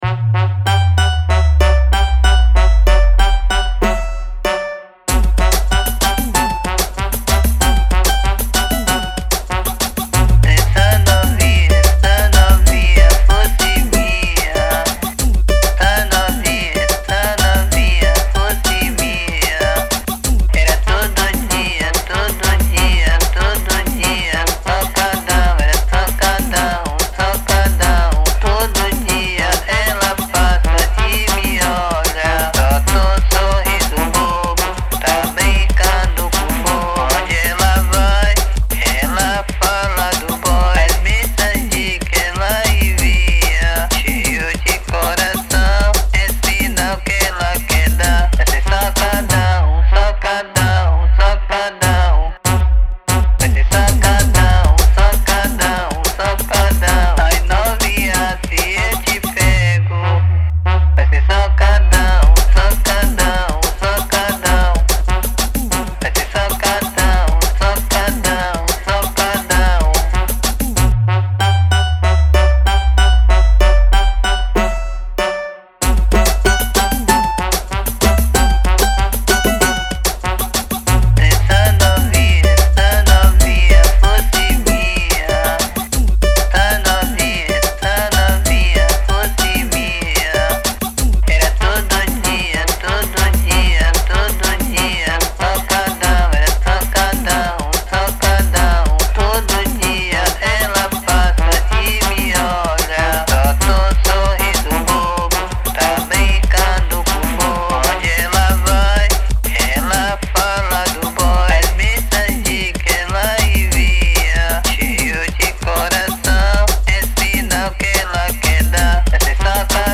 EstiloFunk